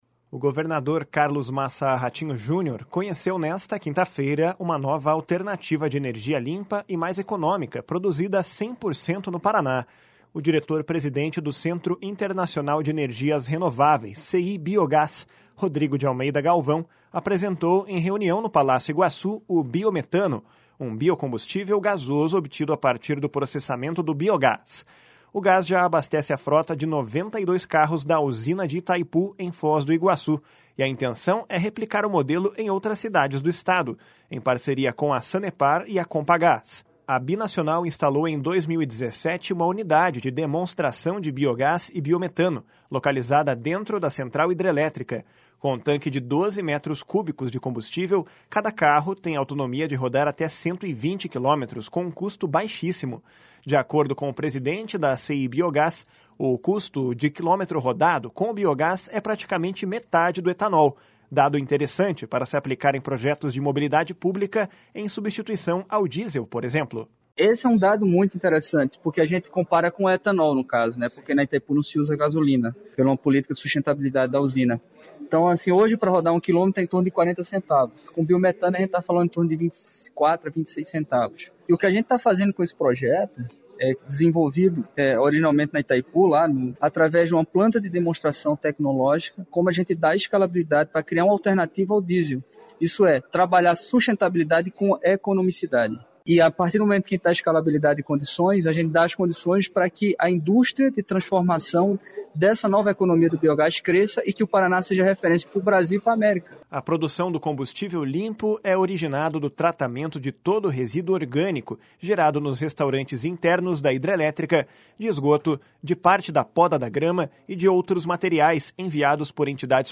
// SONORA MARCELO RANGEL //